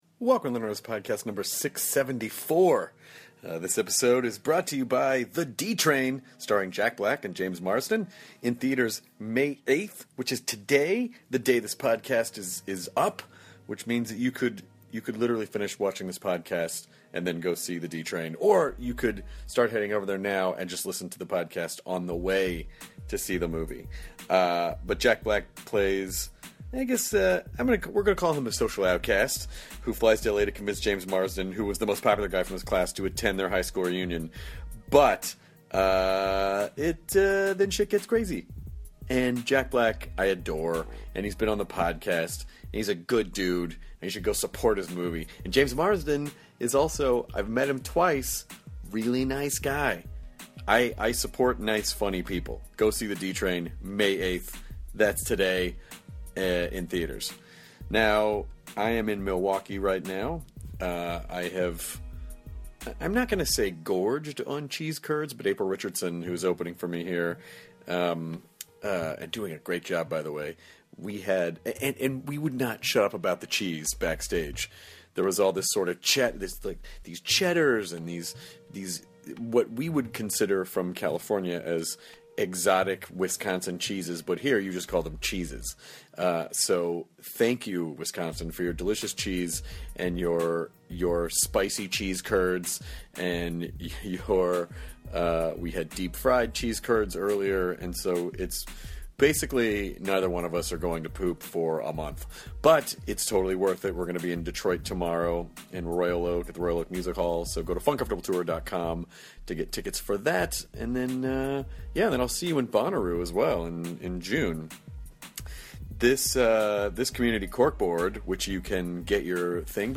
Greg Proops (Whose Line Is It Anyway?, The Smartest Man in the World podcast, comedian) returns to the Nerdist to chat with Chris about people not understanding intelligent jokes, the different craft of writing jokes and how podcasting has allowed Greg to showcase his own comedy style. They also talk about their friendships with Joan Rivers and Greg’s new book The Smartest Book in the World!